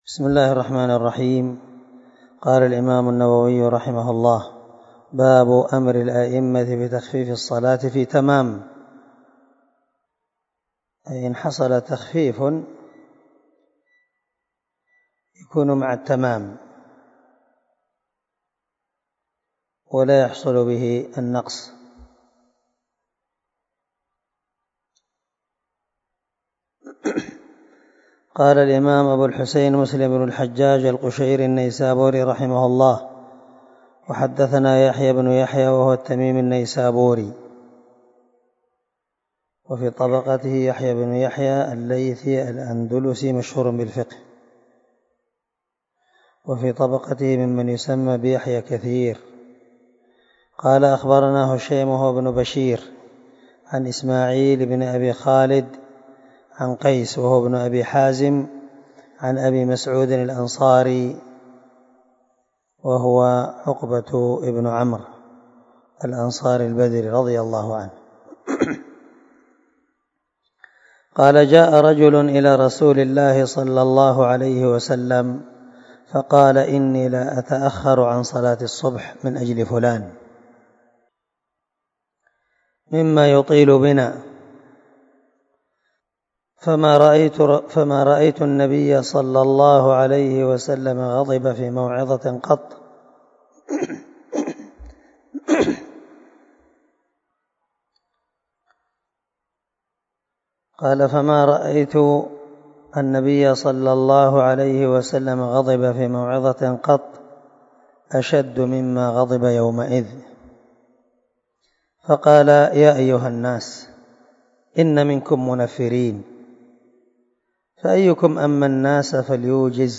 308الدرس 52 من شرح كتاب الصلاة حديث رقم ( 466 – 468 ) من صحيح مسلم
دار الحديث- المَحاوِلة- الصبيحة.